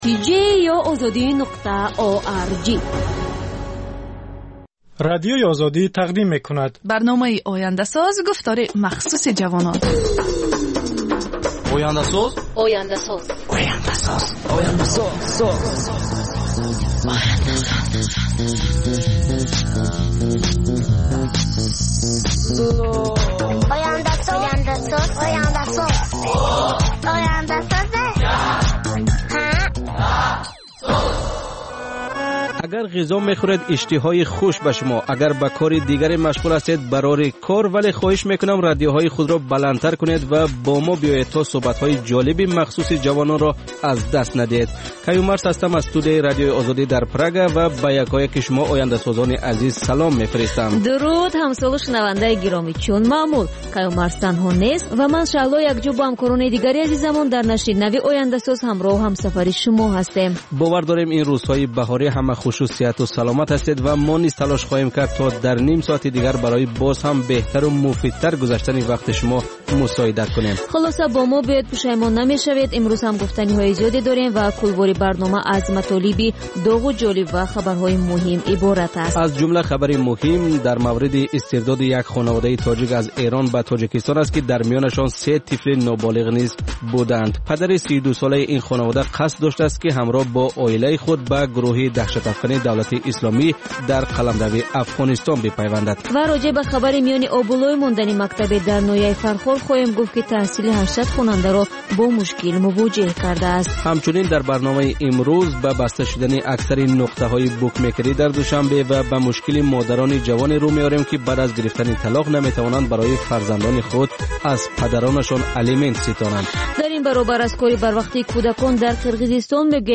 Гуфтори вижаи Радиои Озодӣ аз ҳаёти ҷавонони Тоҷикистон ва хориҷ аз он